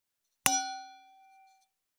306ステンレスタンブラー,シャンパングラス,ウィスキーグラス,ヴィンテージ,ステンレス,金物グラス,
効果音厨房/台所/レストラン/kitchen食器
効果音